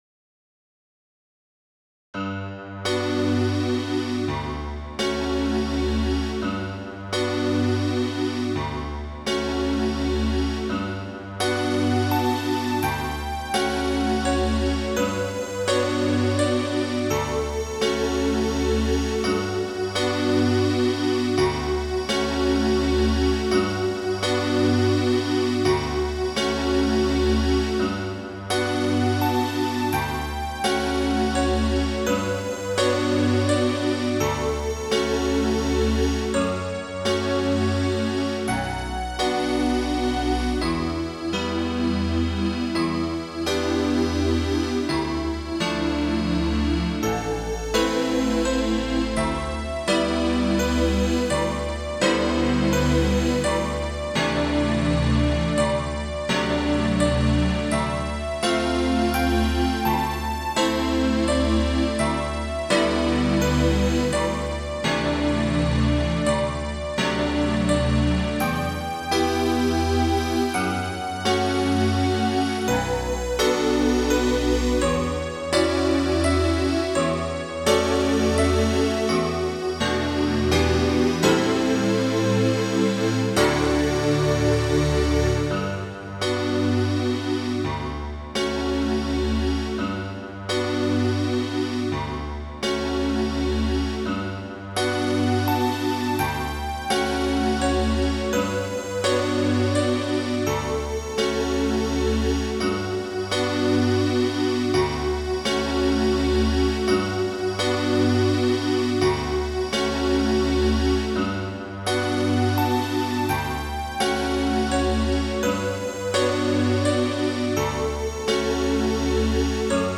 クラシック